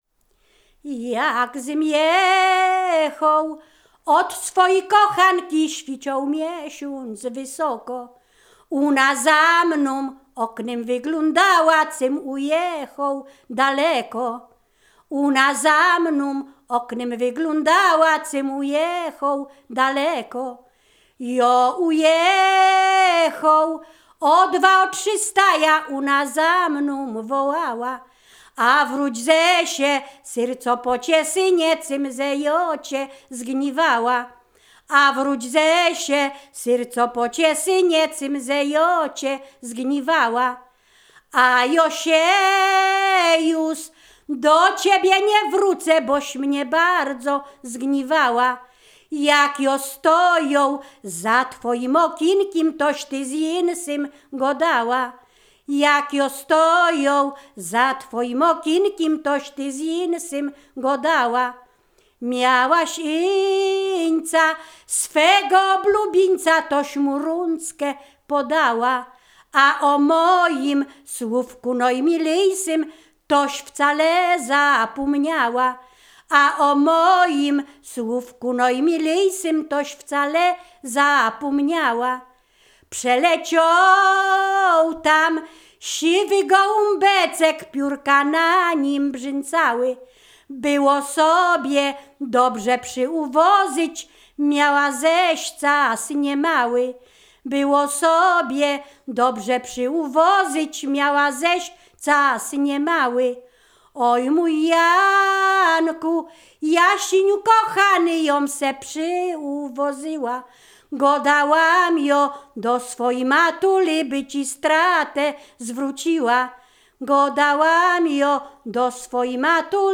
Ziemia Radomska
liryczne miłosne żartobliwe